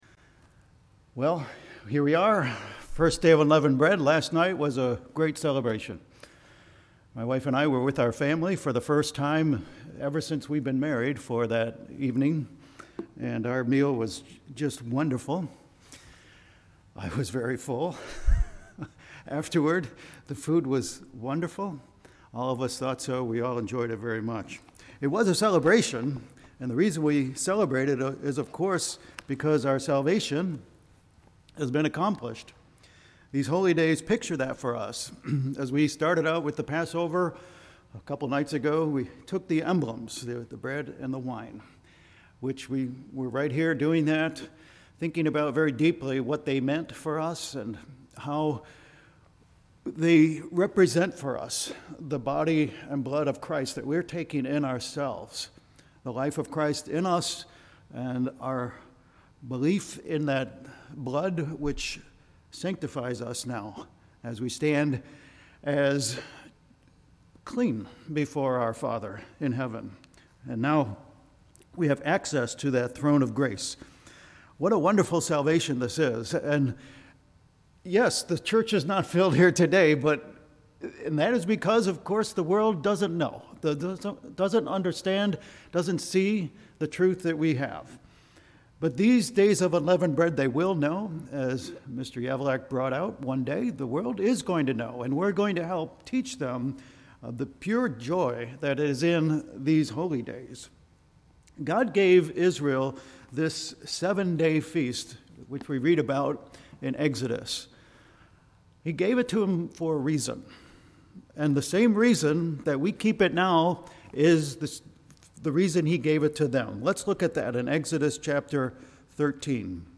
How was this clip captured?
Given in Vero Beach, FL